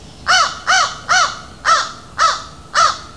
crow2.au